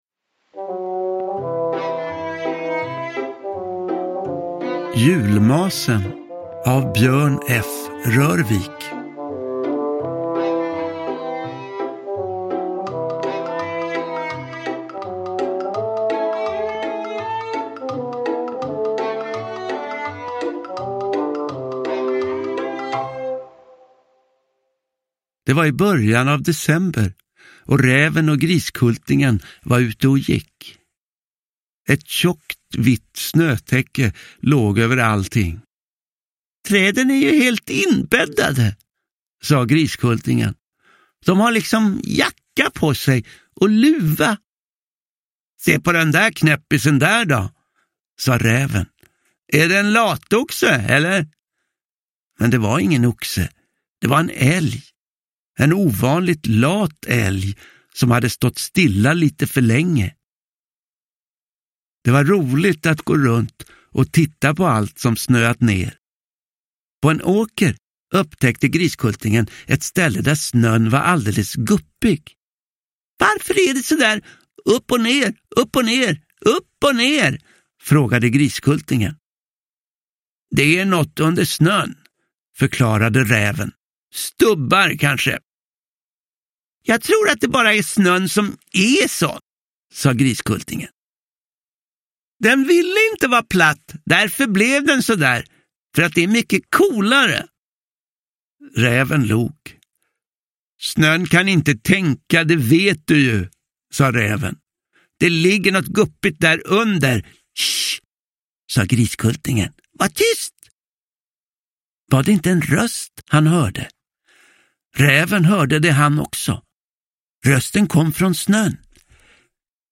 Räven och Griskultingen: Julmasen – Ljudbok – Laddas ner
Uppläsare: Johan Ulveson